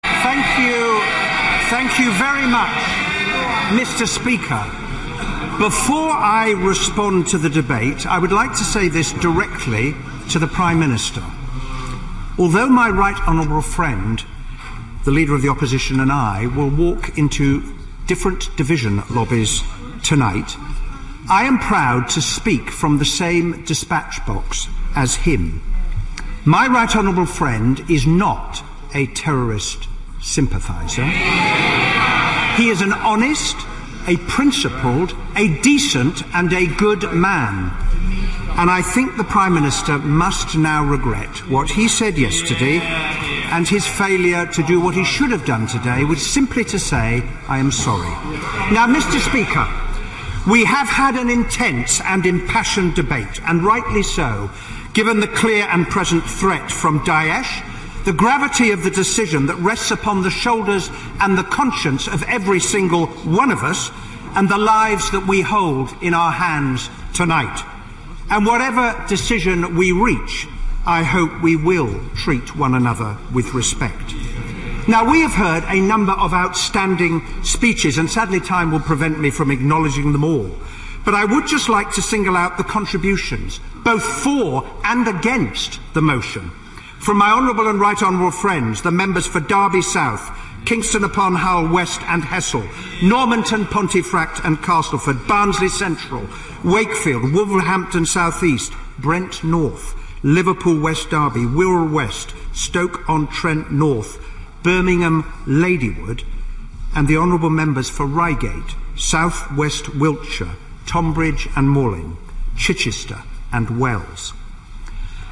欧美名人演讲 第47期:工党影子大臣希拉里·本恩在议会关于空袭ISIS的演讲(1) 听力文件下载—在线英语听力室